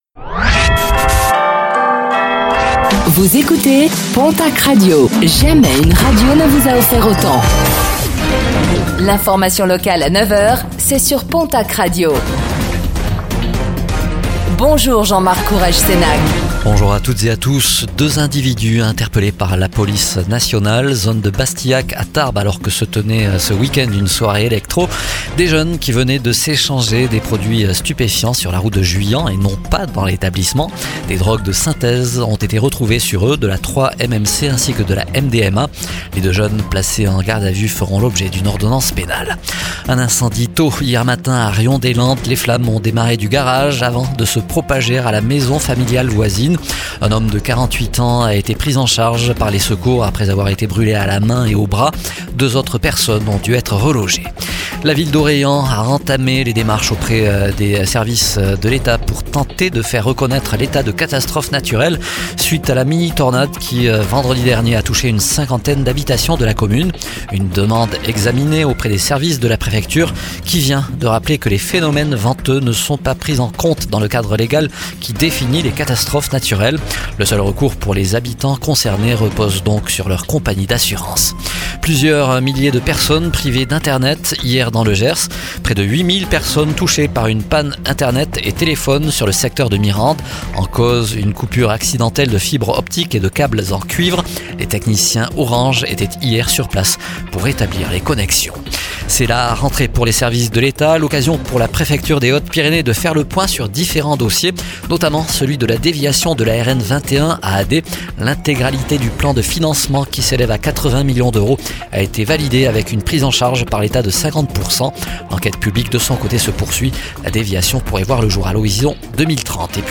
09:05 Écouter le podcast Télécharger le podcast Réécoutez le flash d'information locale de ce mercredi 14 janvier 2026